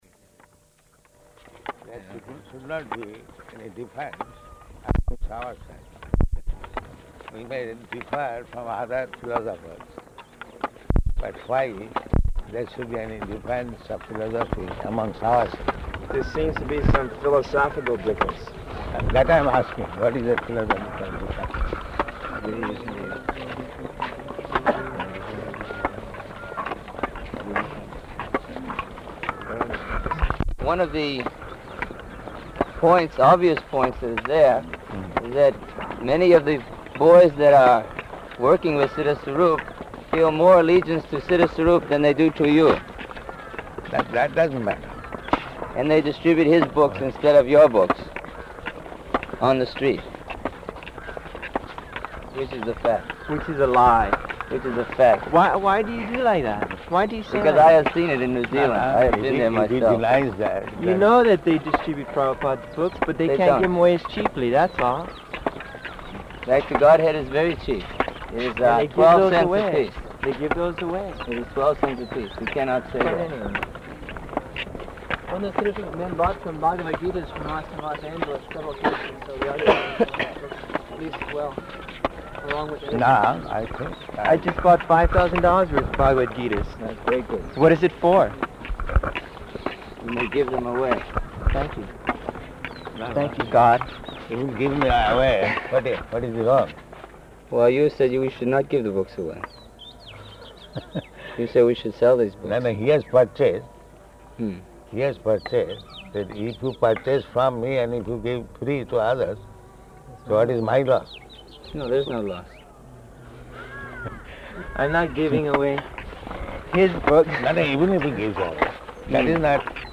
Morning Walk --:-- --:-- Type: Walk Dated: March 8th 1976 Location: Māyāpur Audio file: 760308MW.MAY.mp3 Prabhupāda: ...there should be any difference of amongst ourselves.